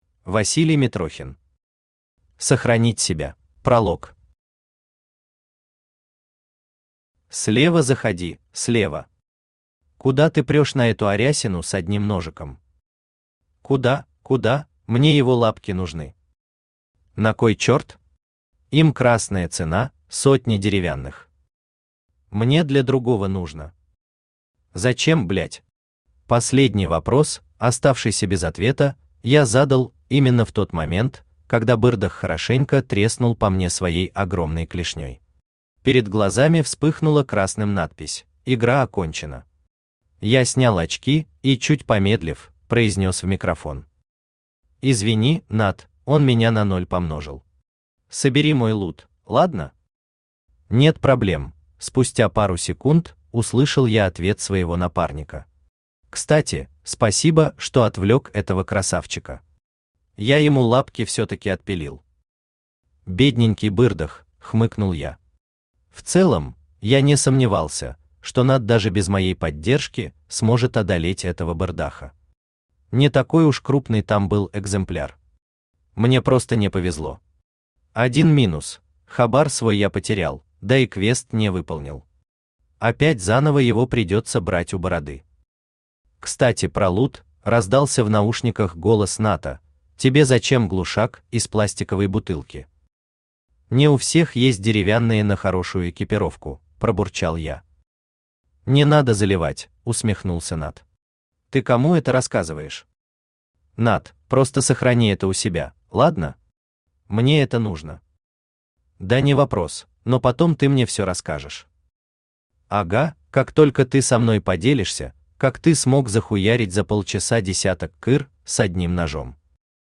Аудиокнига Сохранить себя | Библиотека аудиокниг
Aудиокнига Сохранить себя Автор Василий Митрохин Читает аудиокнигу Авточтец ЛитРес.